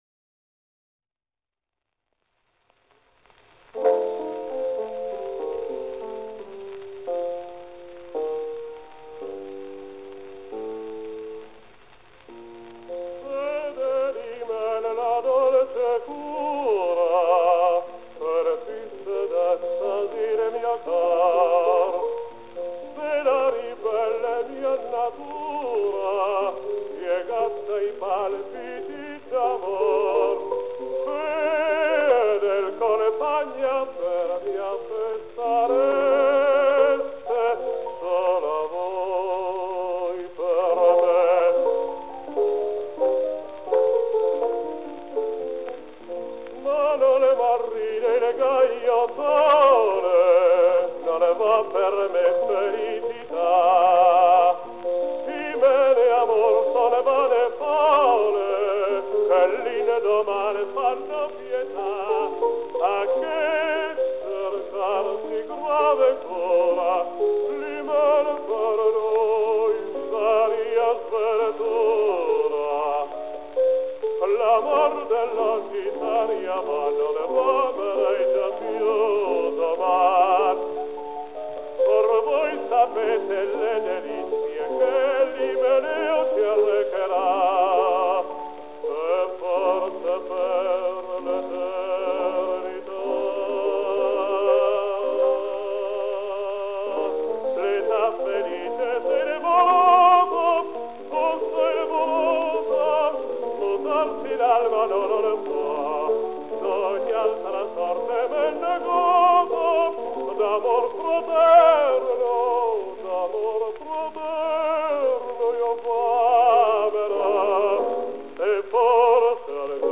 160 лет со дня рождения итальянского певца (баритон) Маттиа Баттистини (Mattia Battistini) !!!!!